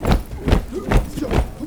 RUNINFEET2-R.wav